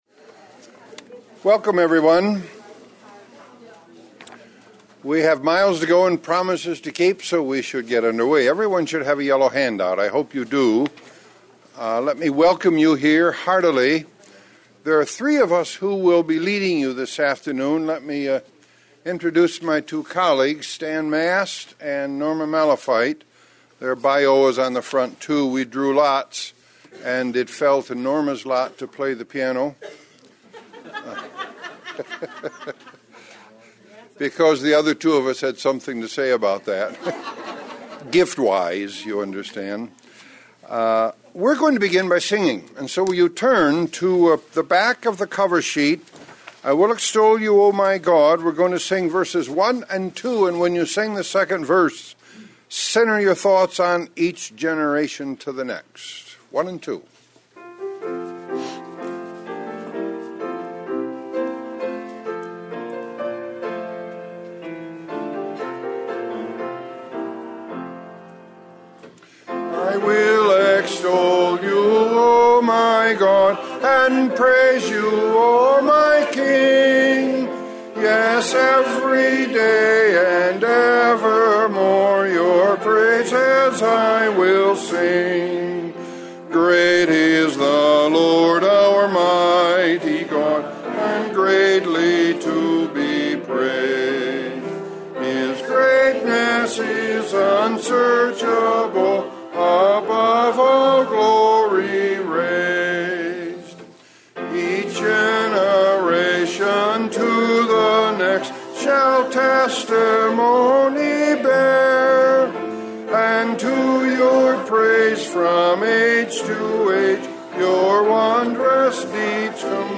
In this presentation, several contributors to the recent book The Church of All Ages: Generations Worshiping Together explored the issues involved, highlighted some of the findings, and stimulated further reflection.
Presented at the 2008 Calvin Symposium on Worship.